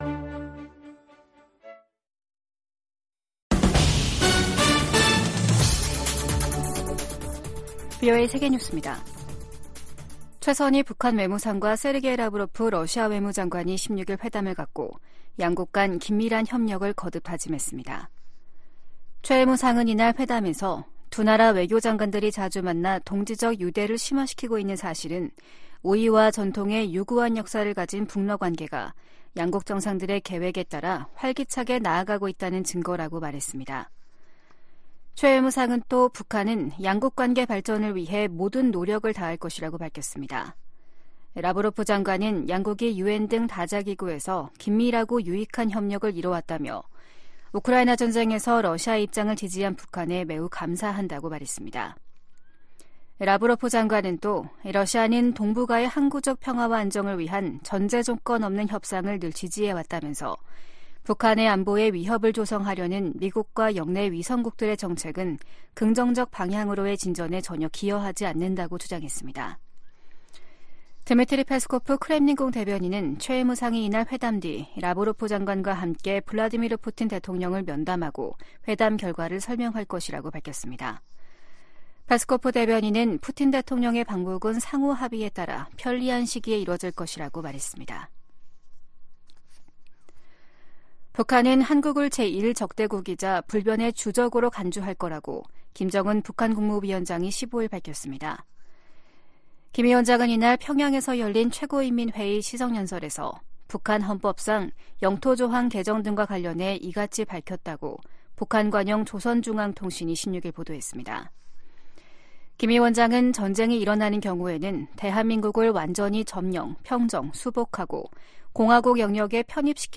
VOA 한국어 아침 뉴스 프로그램 '워싱턴 뉴스 광장' 2024년 1월 17일 방송입니다. 김정은 북한 국무위원장은 한국을 '제1의 적대국'으로 명기하는 헌법개정 의지를 분명히 했습니다.